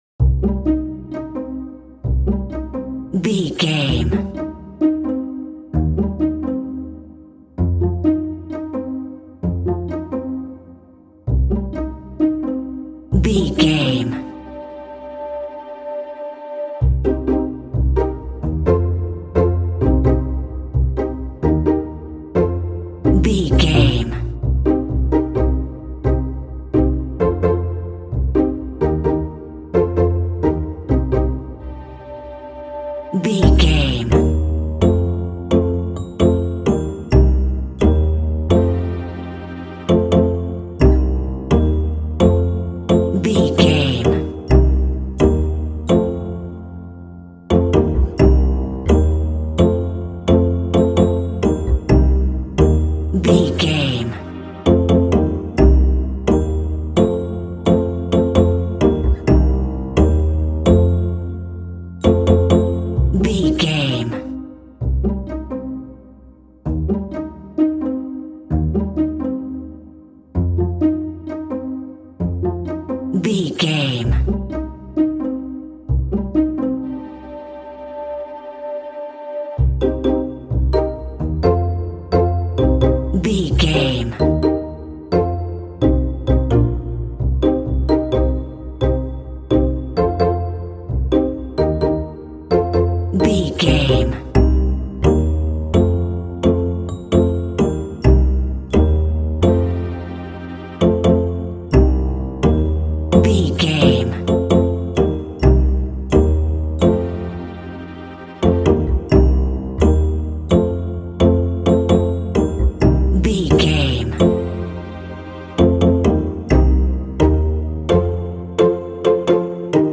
Aeolian/Minor
funny
playful
foreboding
strings
whimsical
cinematic
film score